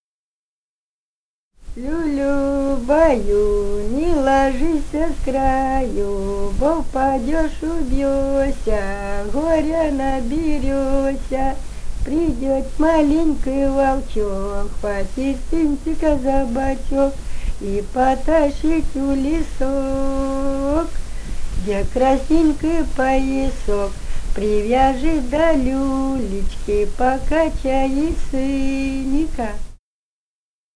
полевые материалы
Румыния, с. Переправа, 1967 г. И0973-14